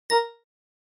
Download Iphone Notification sound effect for free.
Iphone Notification